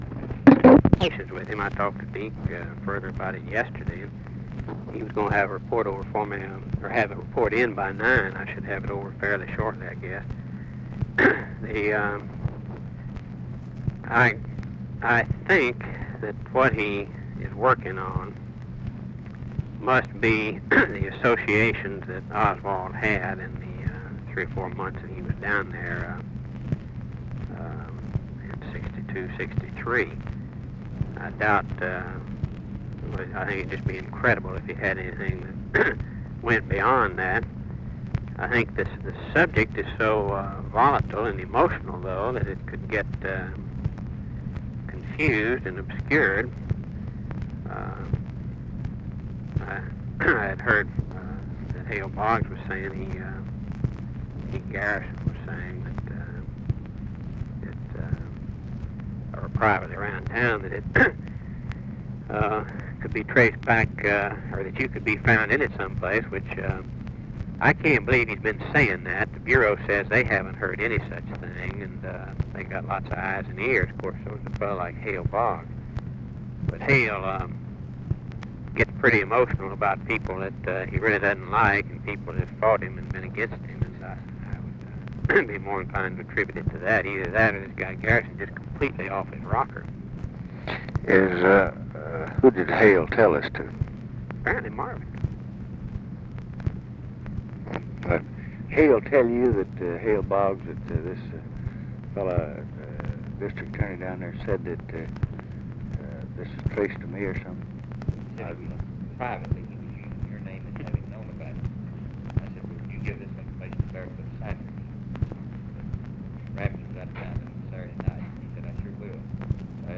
Conversation with RAMSEY CLARK, February 20, 1967
Secret White House Tapes